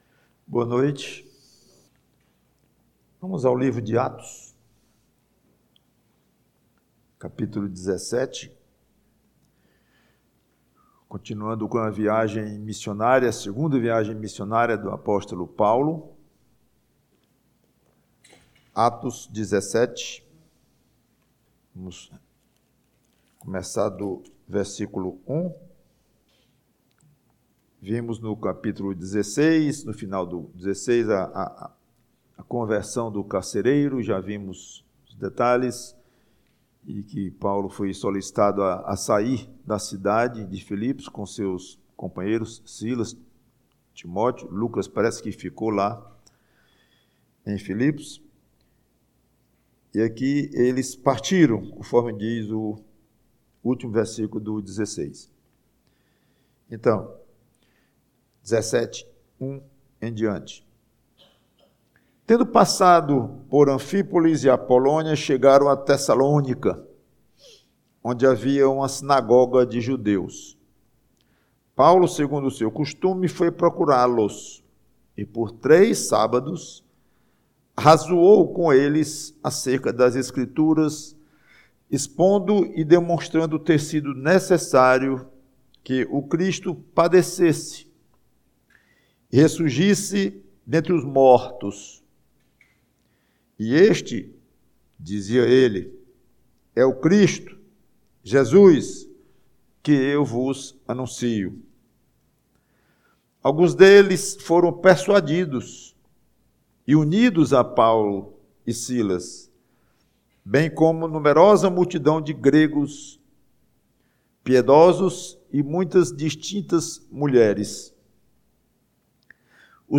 PREGAÇÃO Anunciar Jesus, só isso!